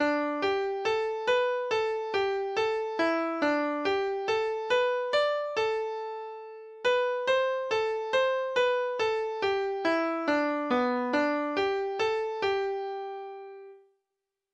Hunden, Thomas Beck - last ned nota Lytt til data-generert lydfil Hunden, Norsk folketone - last ned nota Lytt til data-generert lydfil Melodien er ein variant av folketona som er best kjend til teksta �H�vard Hedde�.